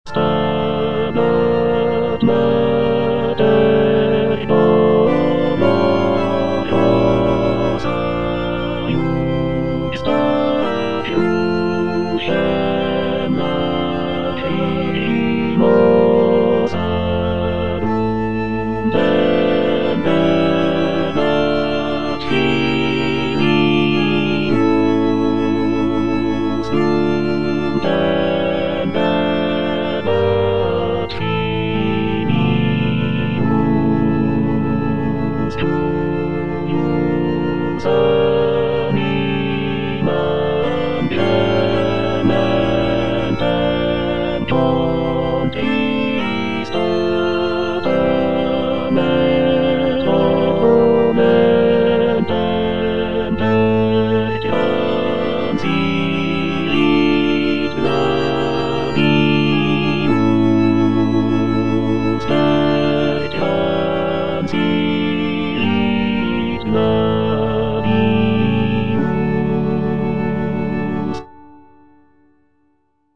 choral-orchestral composition